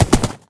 horse_gallop3.ogg